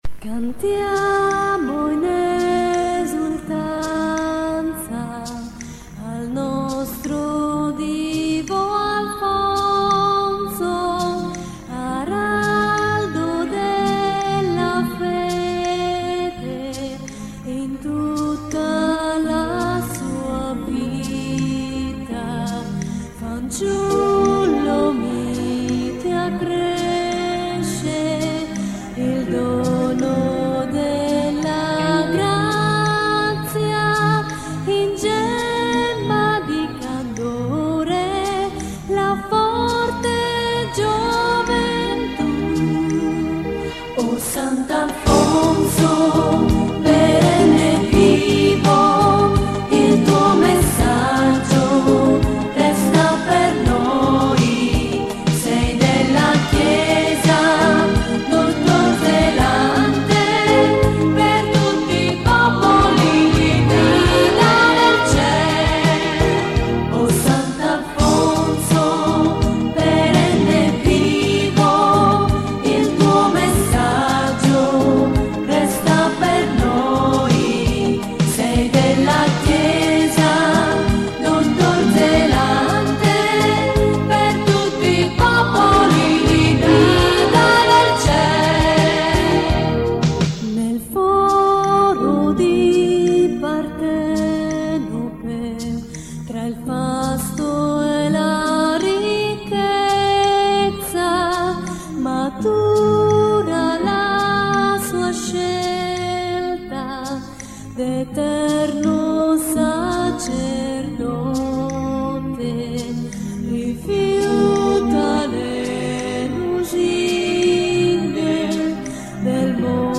L’intero fascicolo è scaricabile in formato pdf; i testi dei singoli canti in formato *.txt. Anche le registrazioni in mp3 hanno valore di demo, cioè un aiuto ad imparare il canto.